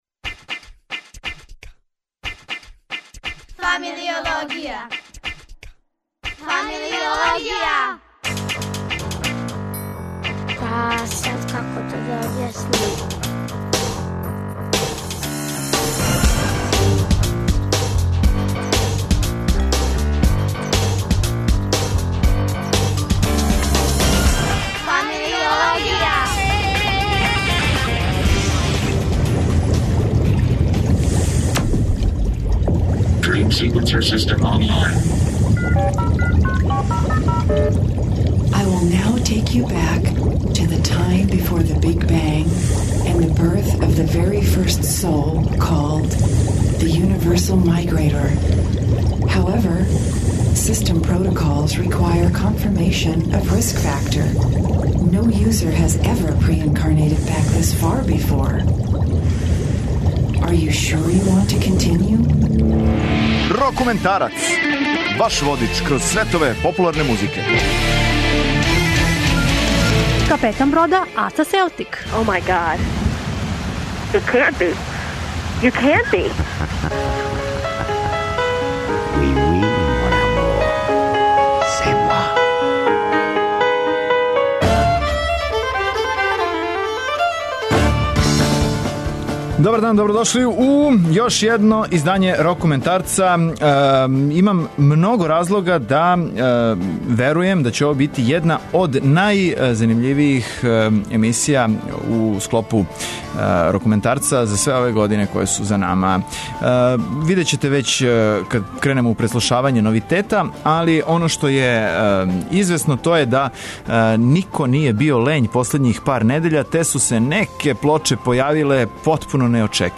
Korpiklaani је фински фолк-метал бенд који је оригинално свирао музику народа Сами, пре него што је придодао метал звук у своје песме.